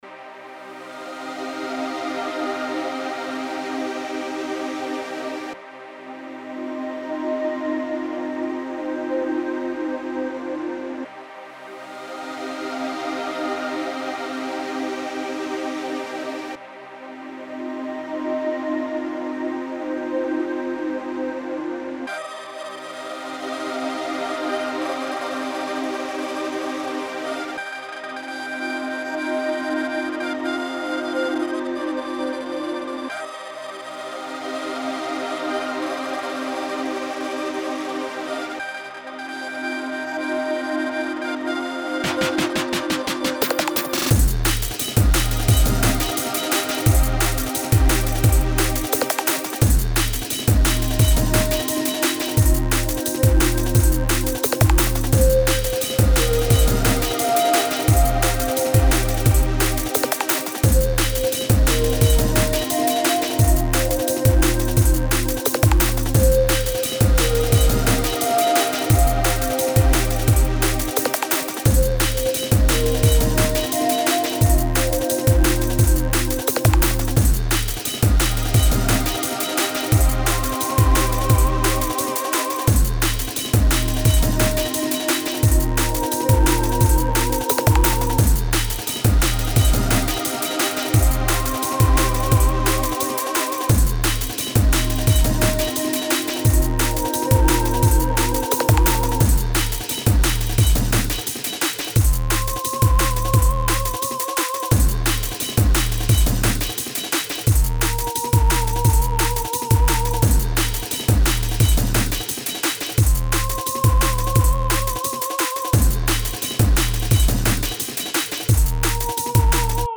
Genre Melodic